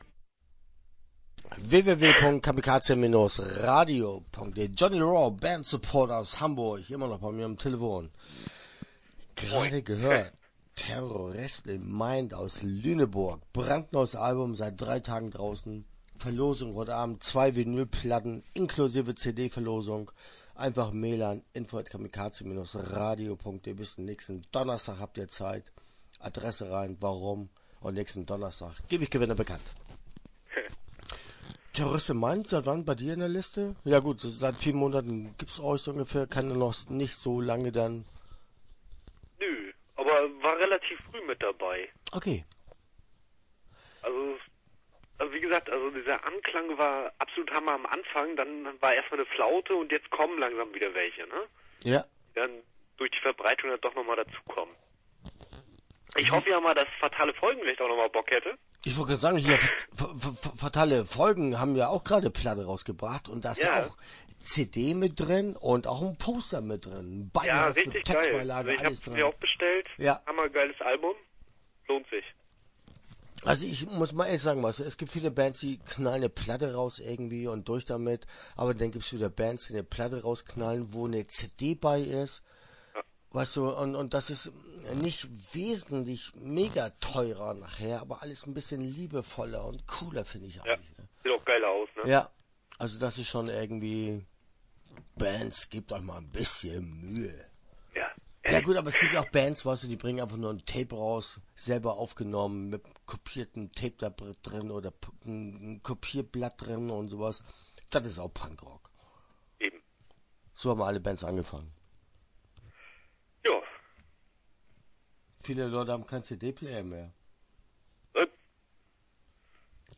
Johnny Raw - Interview Teil 1 (9:18)